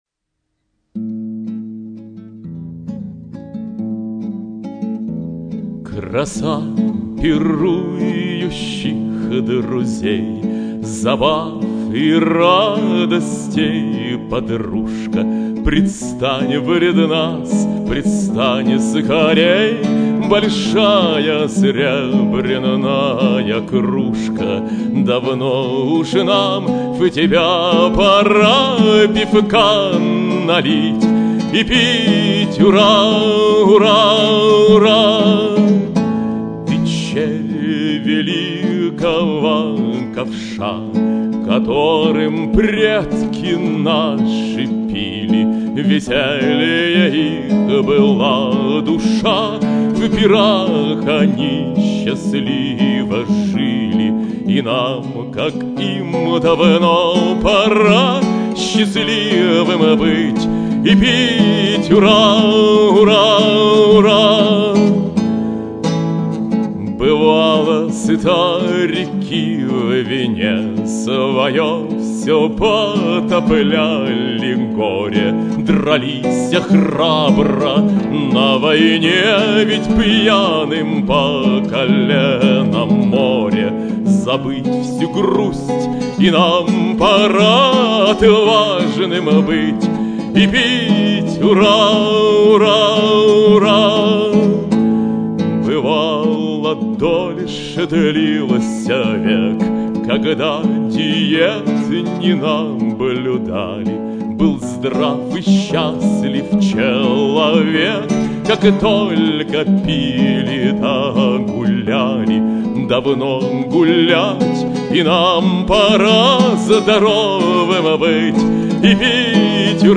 Песня - "Кружка" на стихи Гавриила Романовича Державина: